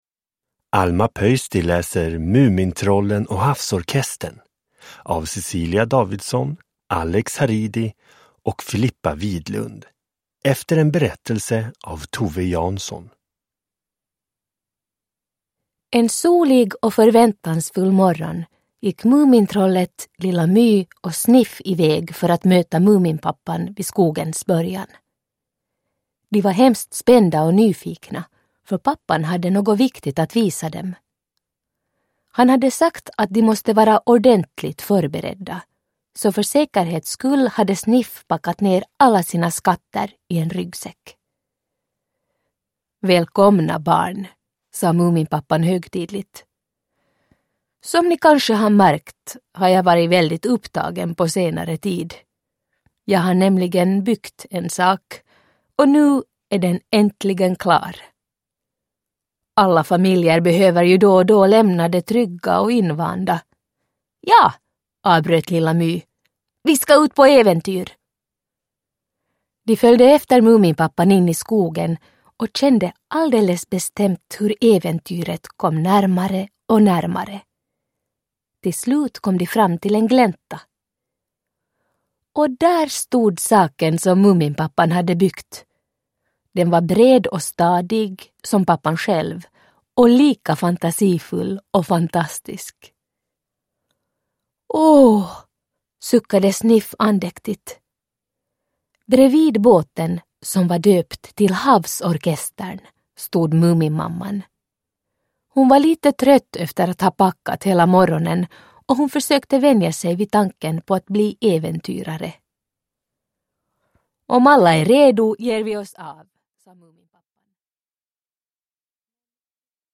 Mumintrollen och havsorkestern – Ljudbok – Laddas ner
Uppläsare: Alma Pöysti